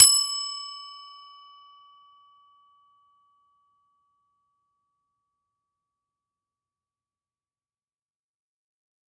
Various SFX